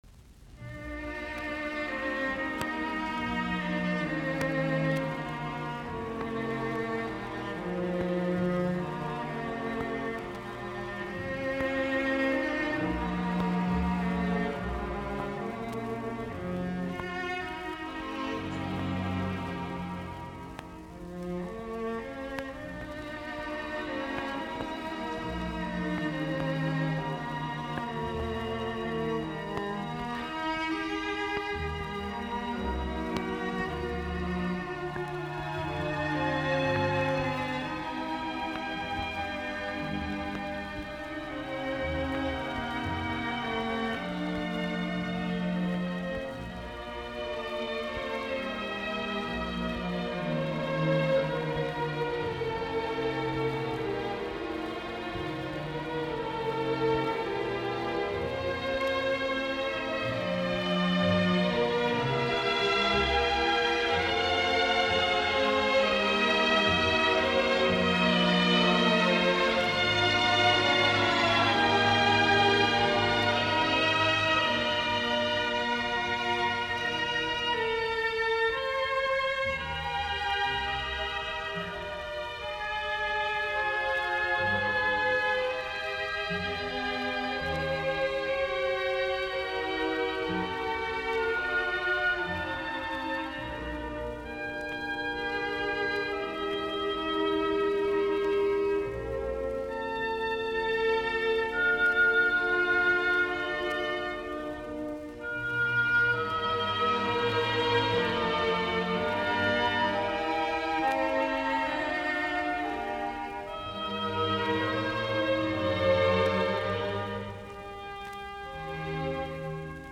Concerto No. 2, in B-flat, op. 83
Allegro non troppo SIB_LP00090_A_01.mp3 - 14.35 MB 2.